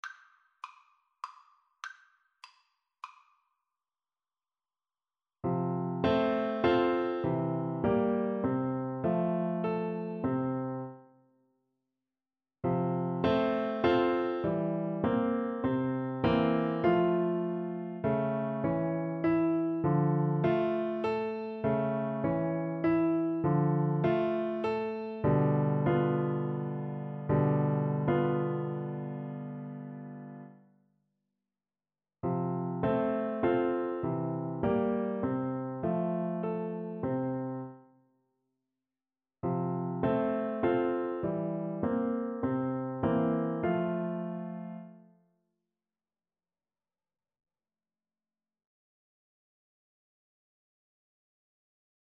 Play (or use space bar on your keyboard) Pause Music Playalong - Piano Accompaniment Playalong Band Accompaniment not yet available reset tempo print settings full screen
F major (Sounding Pitch) (View more F major Music for Oboe )
3/4 (View more 3/4 Music)
Allegro moderato (View more music marked Allegro)
Classical (View more Classical Oboe Music)